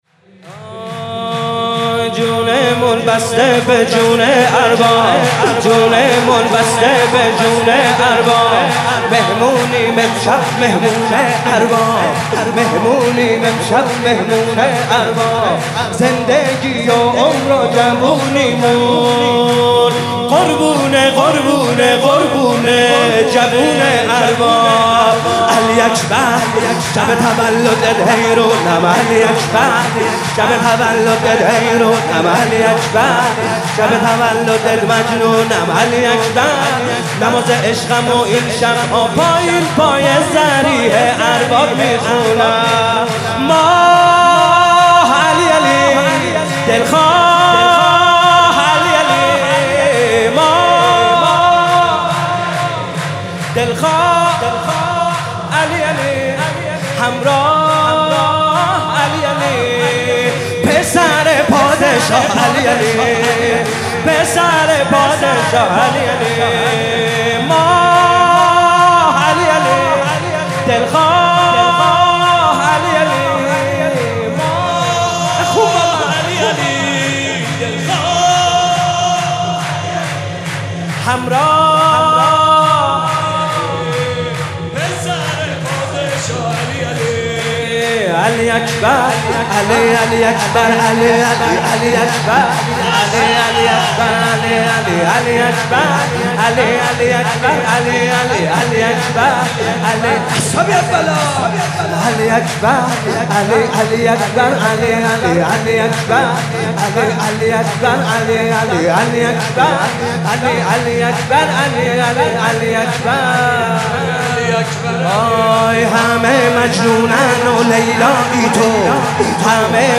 سرود جدید